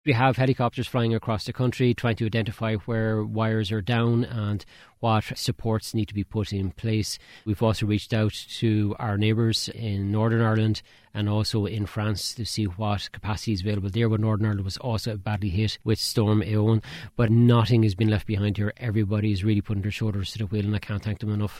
Housing Minister James Browne says all efforts are being made to reach everyone affected: